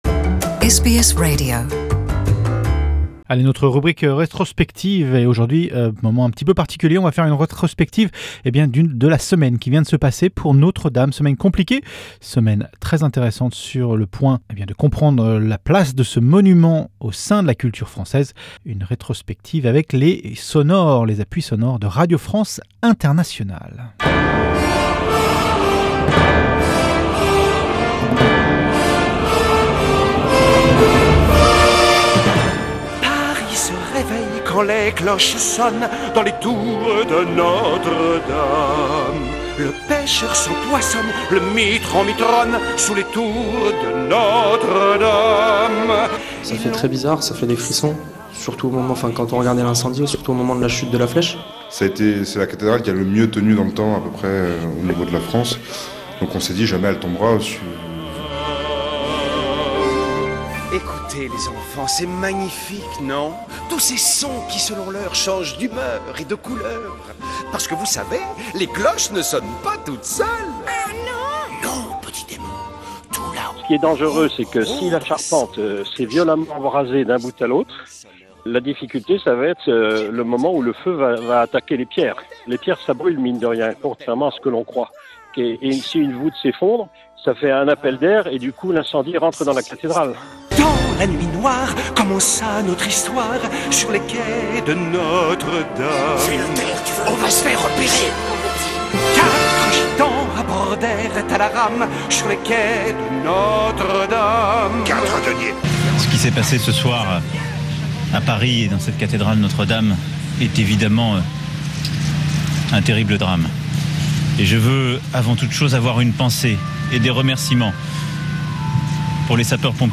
Notre Dame: Retour sur une semaine désastreuse, réalisation avec l'appui des sonores de RFI